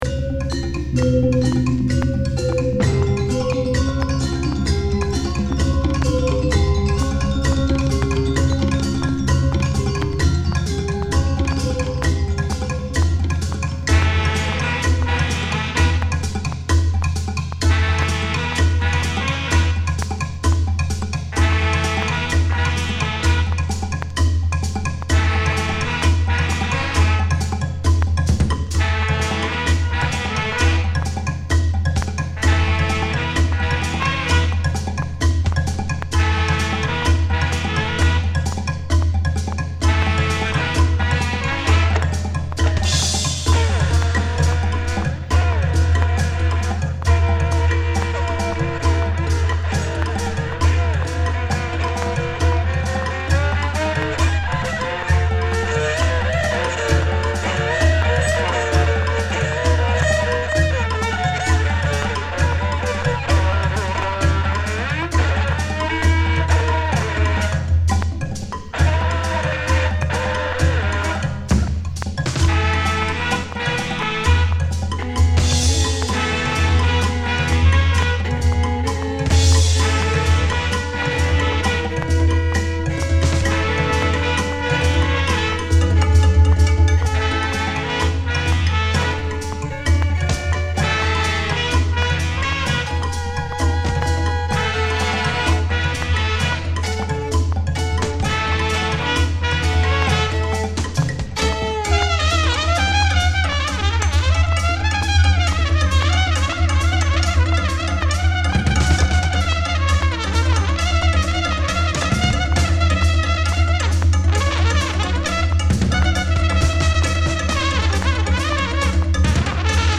ルーツミュージックをヒップホップ的リズム感で繋いでいく唯一無二のDJスタイルで、
本作では、ポリリズムを持ったドラムやパーカッションなどを主体にプログレッシブな選曲、ミックスを展開。
DJ/トラックメーカー